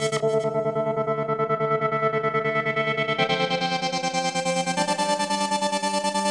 synth.wav